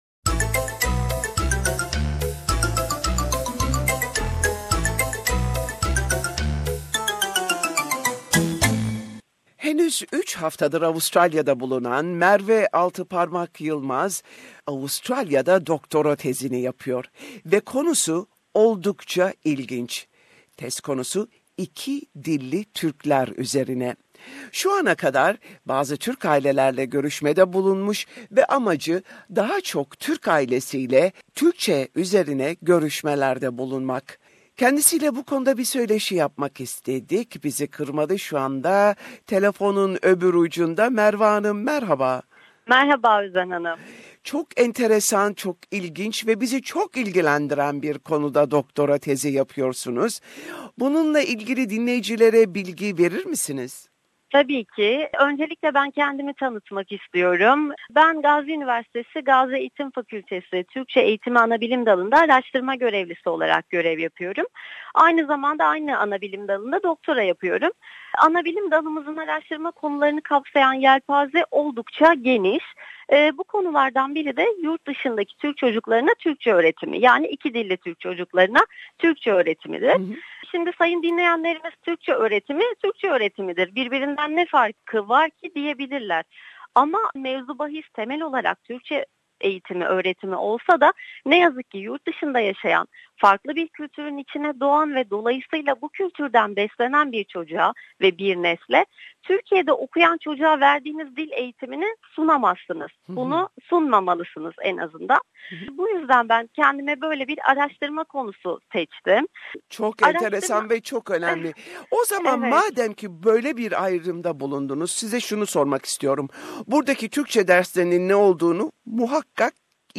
Interview on Speaking Two Languages